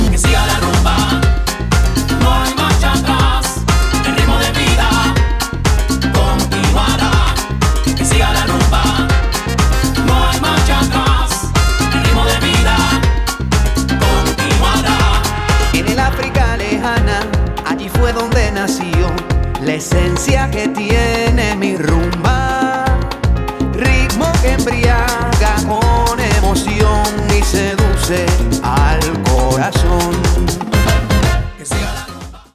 LATIN TROPICAL SOUL EXPERIENCE
The EDM Latin dance mixes